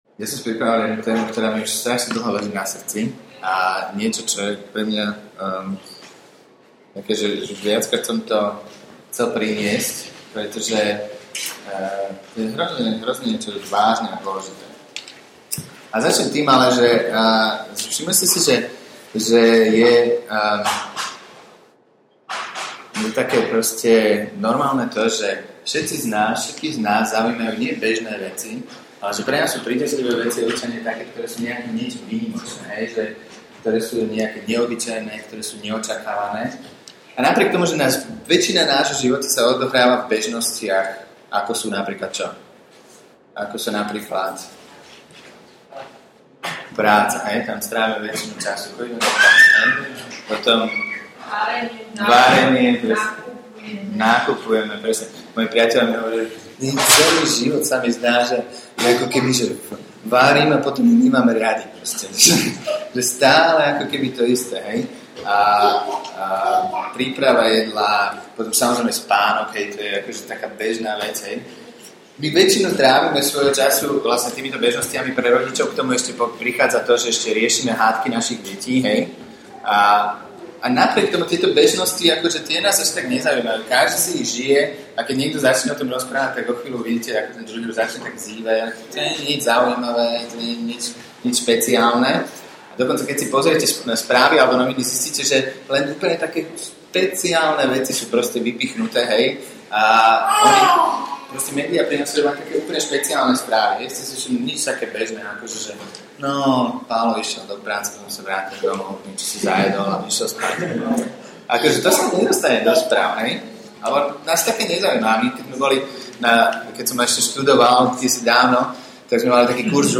Nahrávka kázne Kresťanského centra Nový začiatok z 6. júla 2008